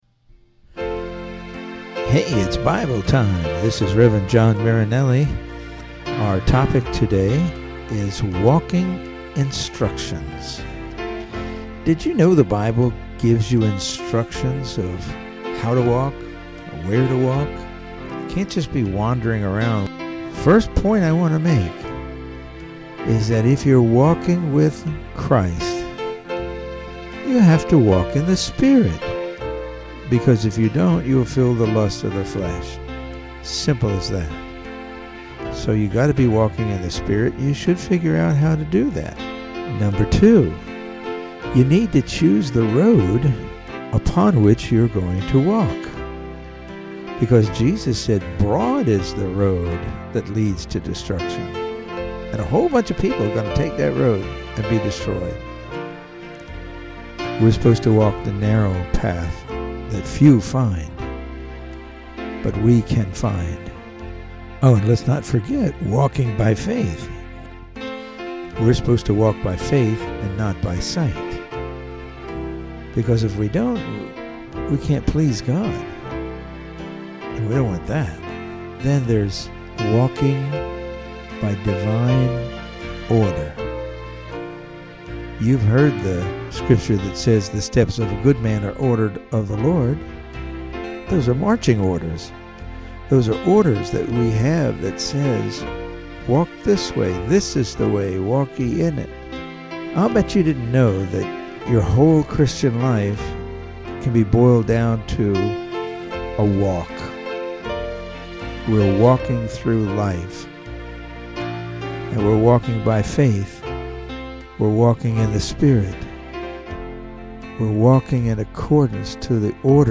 Welcome to Bible Time. The audio files are all 3-6 minute devotional messages that are meant to encourage and